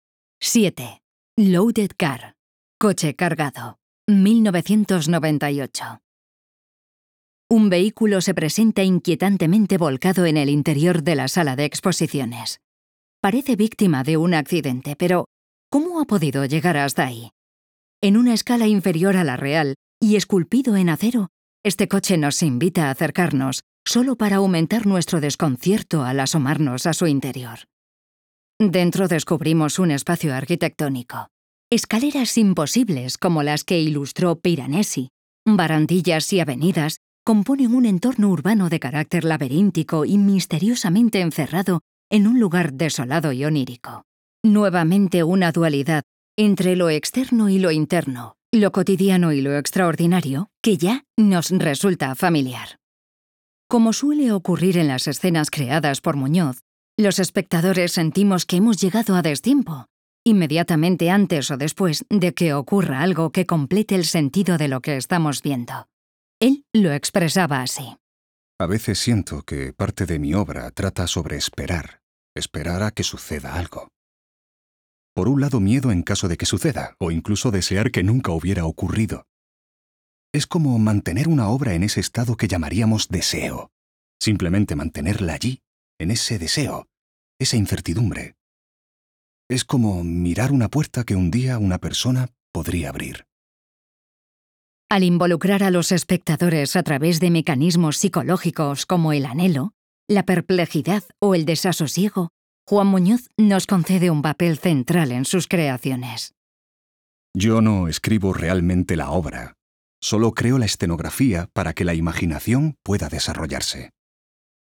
Audioguide "Juan Muñoz"